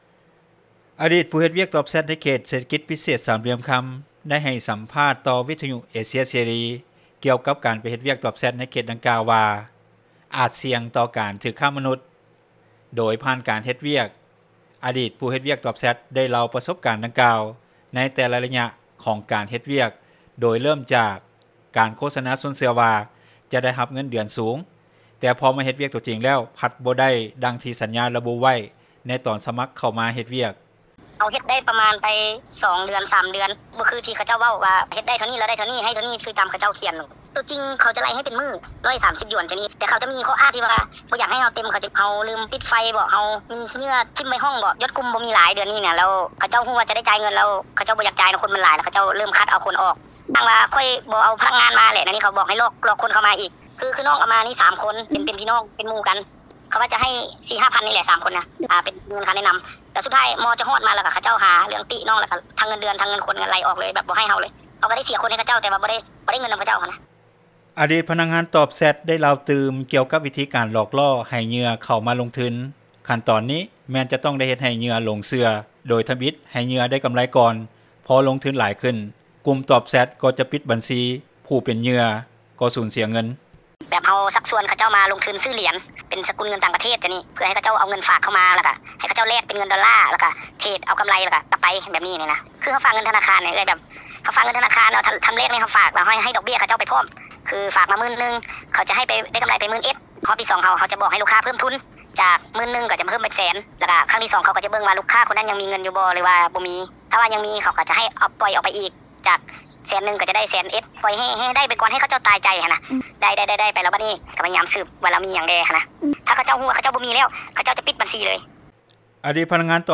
ອະດີດຜູ້ເຮັດວຽກຕອບແຊັດ ໃນເຂດເສດຖະກິດພິເສດ ສາມຫຼ່ຽມຄໍາໄດ້ໃຫ້ສໍາພາດຕໍ່ວິທຍຸເອເຊັຽເສຣີກ່ຽວກັບການໄປເຮັດວຽກຕອບແຊັດ ໃນເຂດດັ່ງກ່າວວ່າ ອາດສ່ຽງຕໍ່ການຖືກຄ້າມະນຸດ ໂດຍຜ່ານການເຮັດວຽກ.